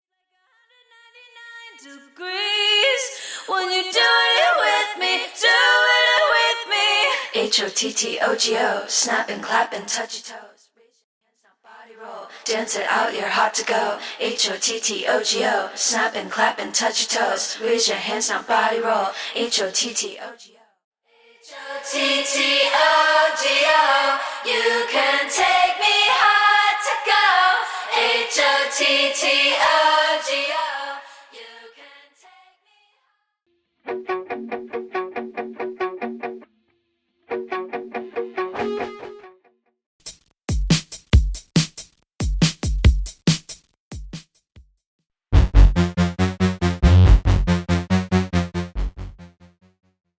(Studio Adlibs & Strings Stem)
(Studio All Bassline Stem)
(Studio Leading Guitars Stem)
(Studio Percussion & Drums Stem)